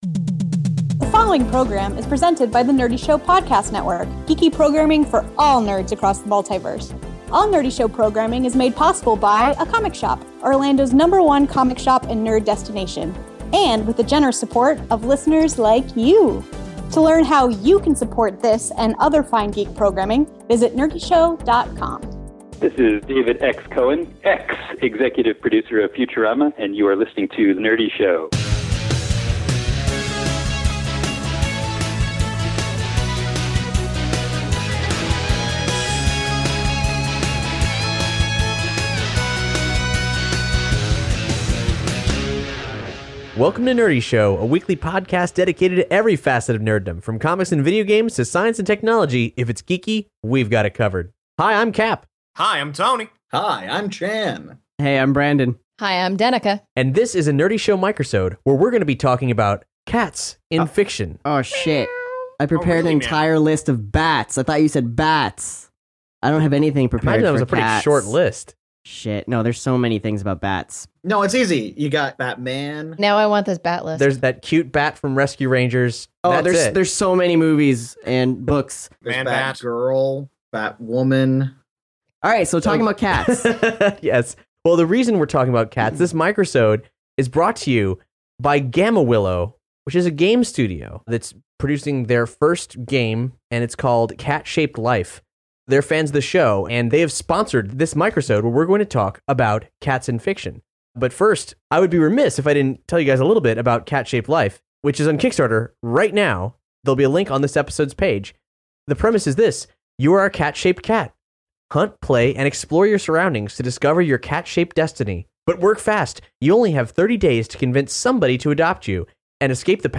In this episode we gather under the light of a Jellicle moon to put on an impromptu "Naming of Cats".